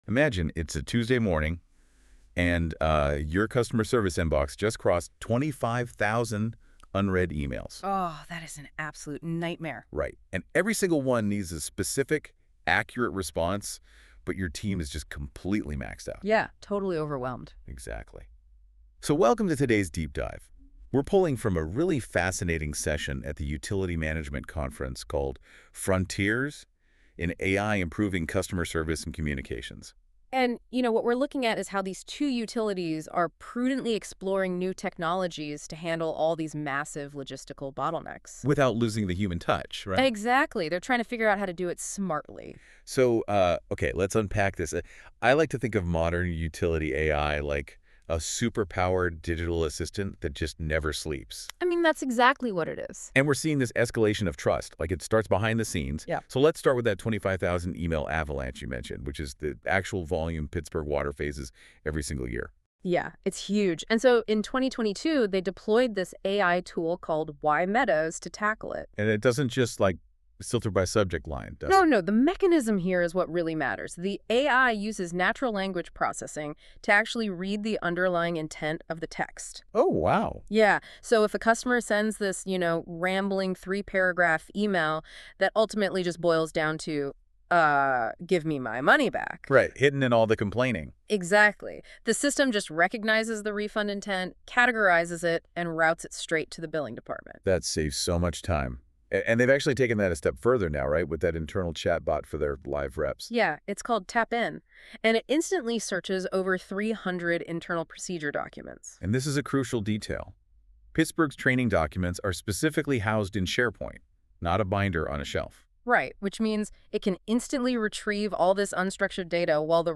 AI Generated Podcasts